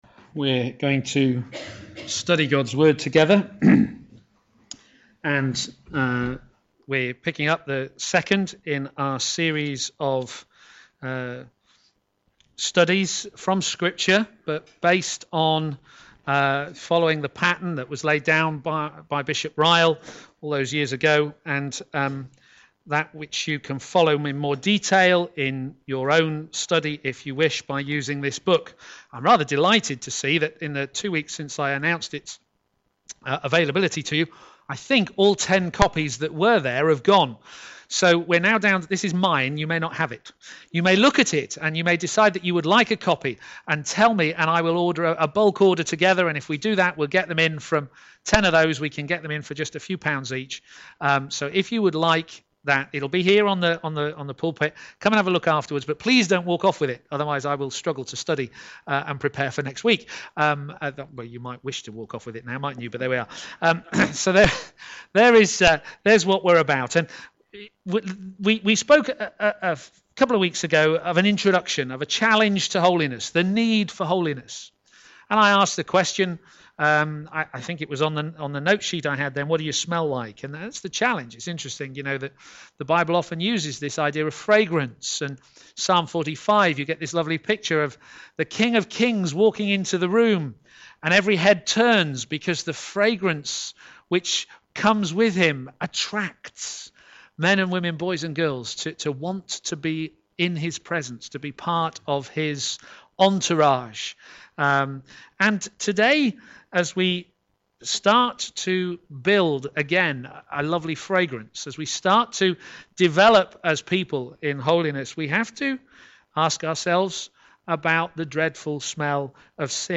Media for a.m. Service on Sun 24th Apr 2016 10:30
Sermon